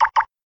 Door_Lock.ogg